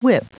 Prononciation du mot whip en anglais (fichier audio)
Prononciation du mot : whip